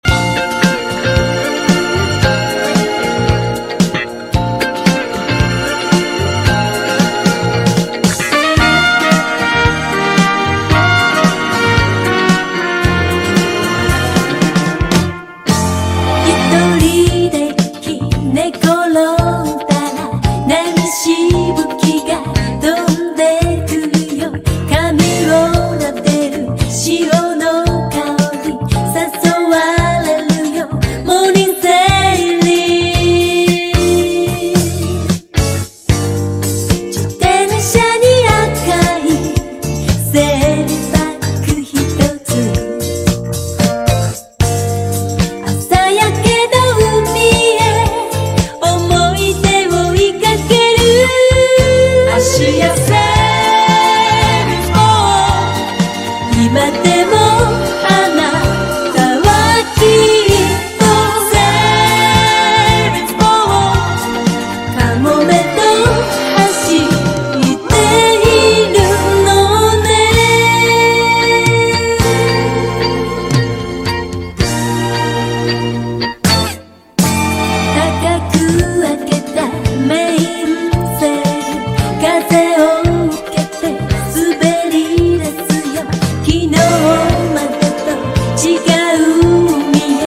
JAPANESE / 80'S / TECHNO POP / テクノ歌謡 / SYNTH POP (JPN)
中身はというと、プログレ風味の危ういハード・シンセ・ポップが並んでいるのですが、